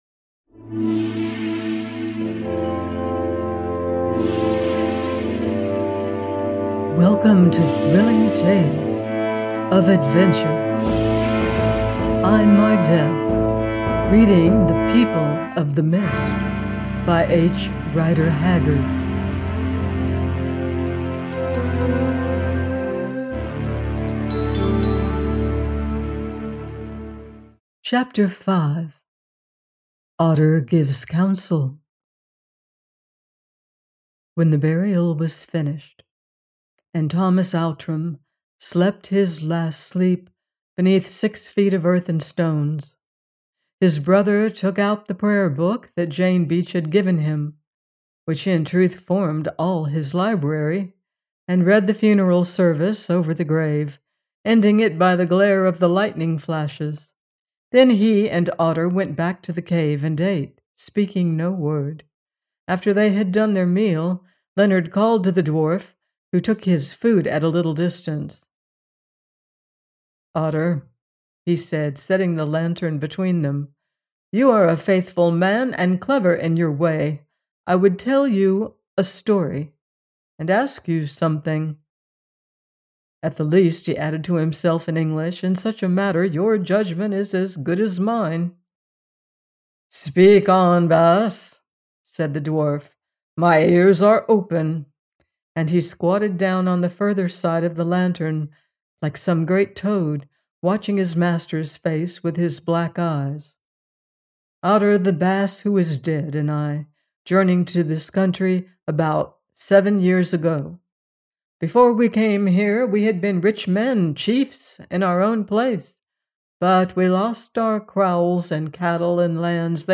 The People of the Mist – 5 : by H. Rider Haggard - audiobook